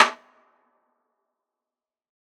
Plastic Wood Perc.wav